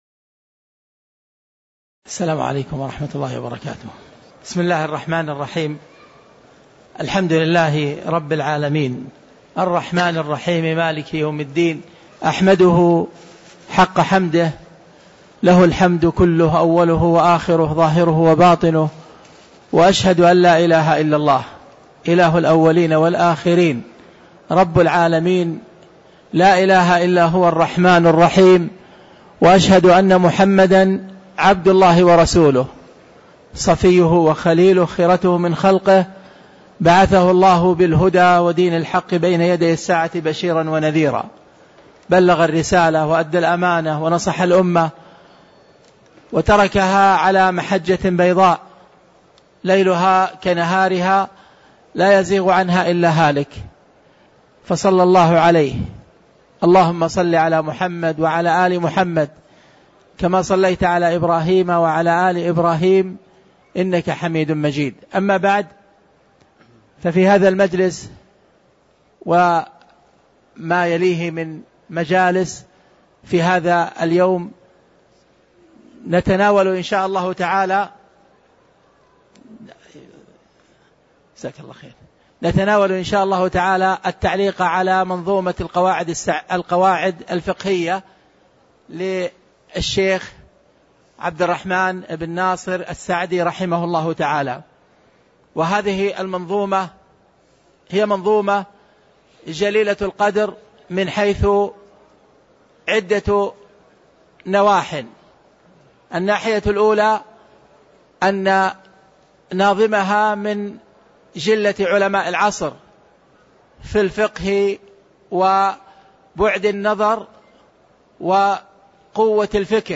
تاريخ النشر ١٩ شوال ١٤٣٧ هـ المكان: المسجد النبوي الشيخ